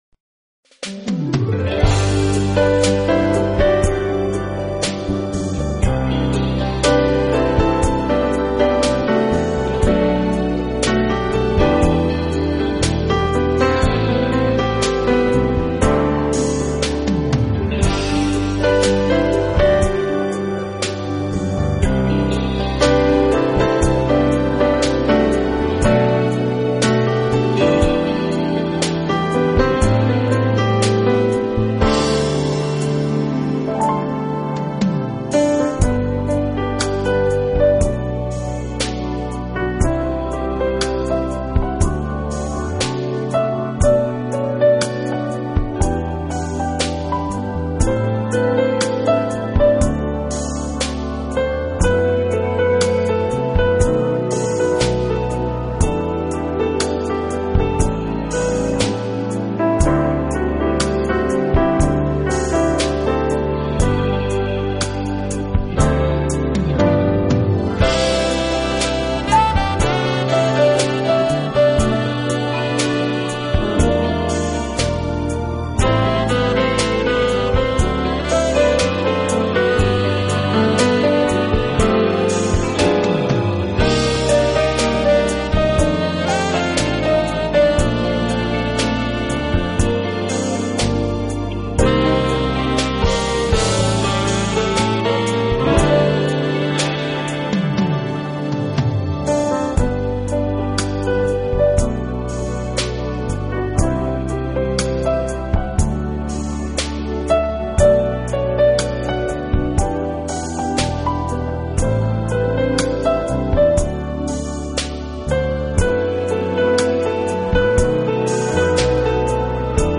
呈现，间或加入的萨克斯、长笛、吉他以及口琴，就如克里姆特擅长运用